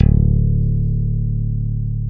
Index of /90_sSampleCDs/Roland LCDP02 Guitar and Bass/BS _Stik & Dan-O/BS _Chapmn Stick